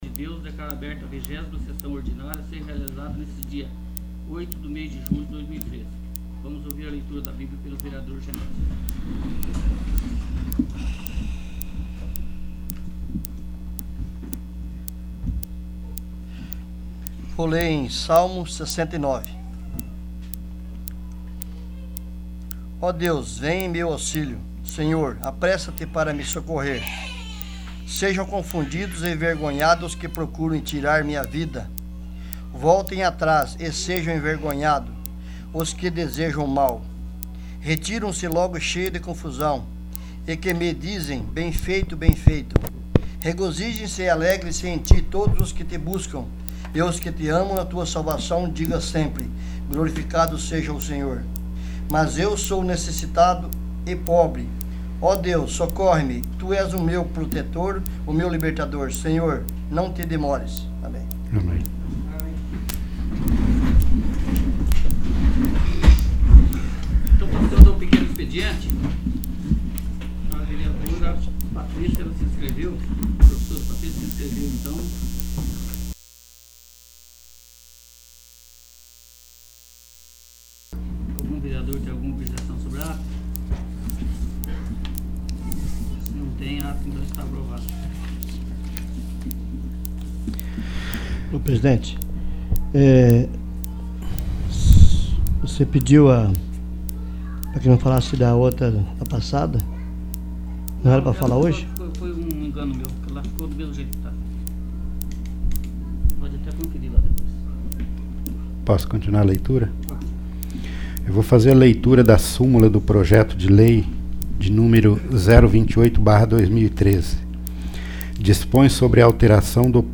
20º. Sessão Ordinária